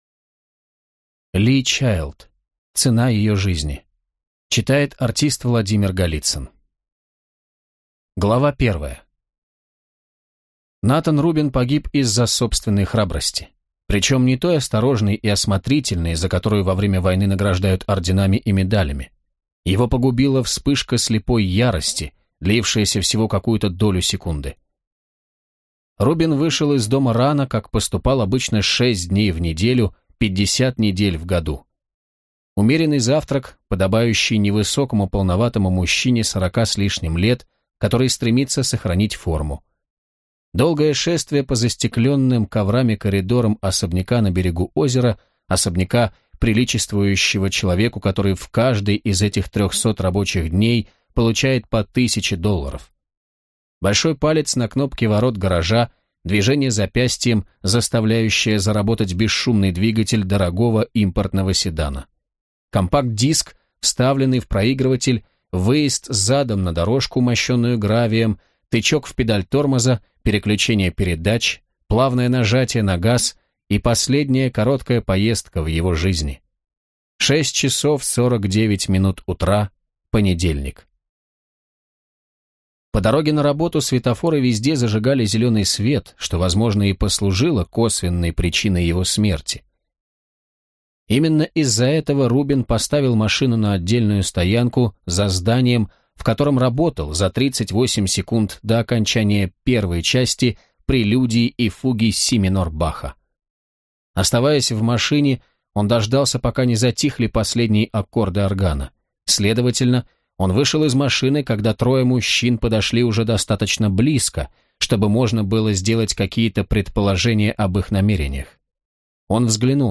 Аудиокнига Джек Ричер, или Цена ее жизни | Библиотека аудиокниг